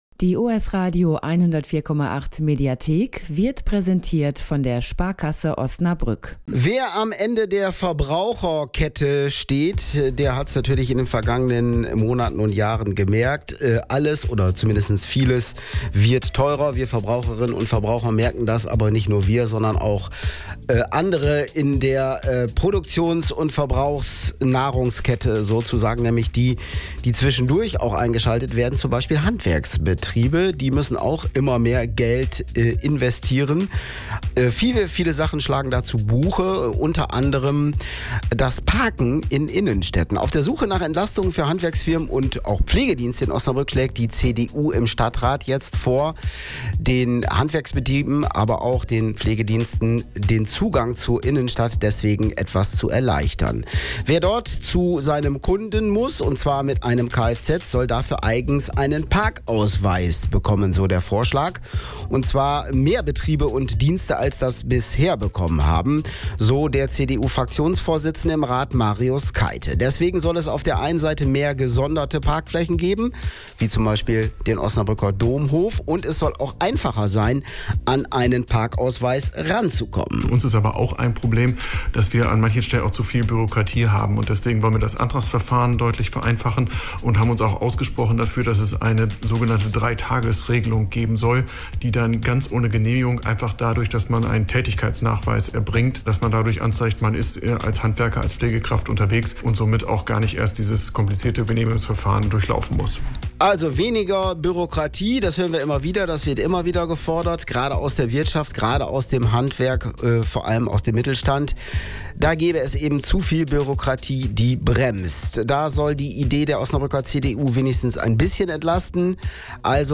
Ziel sei es, mehr Betrieben als bisher das Parken bei Kundenterminen zu ermöglichen, erklärt Fraktionsvorsitzender Marius Keite gegenüber OS-Radio 104,8.